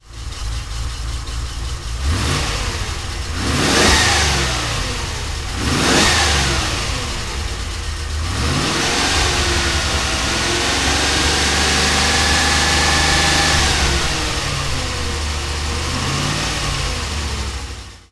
Description Rover-v8-rr79.ogg English: Sound clip of a 3.5 litre Rover V8 engine, as fitted to a 1979 Range Rover.
Rover-v8-rr79.ogg